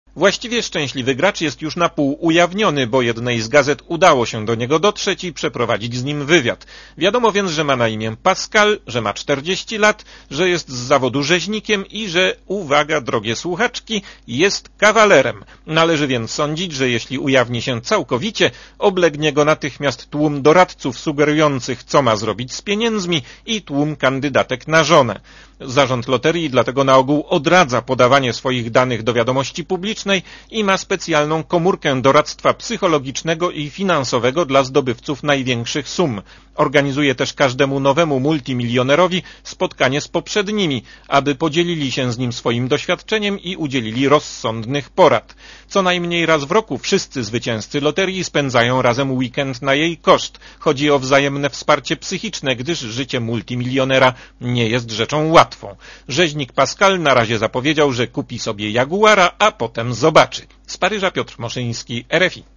Relacja z Paryża Oceń jakość naszego artykułu: Twoja opinia pozwala nam tworzyć lepsze treści.